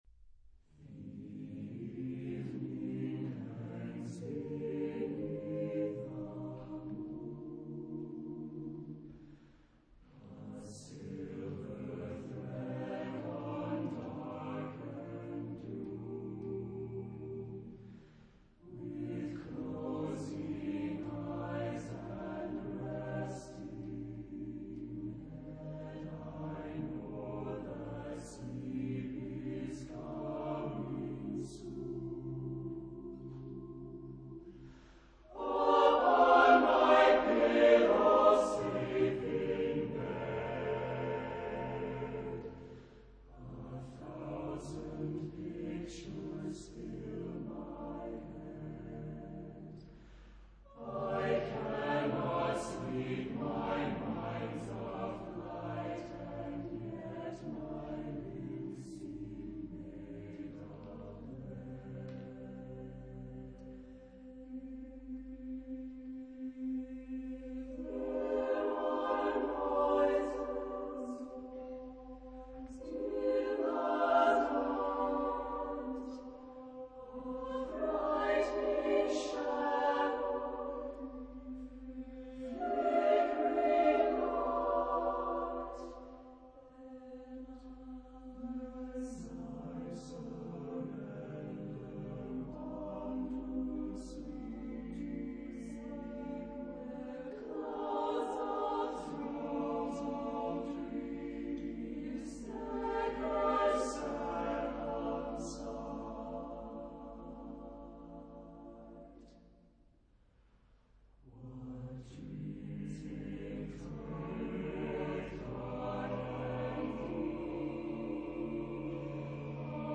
Genre-Style-Forme : Profane ; Poème ; Chœur
Caractère de la pièce : contrasté ; rêveur
Type de choeur : SATB  (4 voix mixtes )
Tonalité : mi bémol majeur ; clusters
Consultable sous : 20ème Profane Acappella